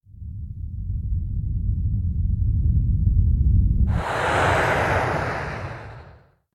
rocket_launch.ogg